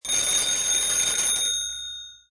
telephone_ring.mp3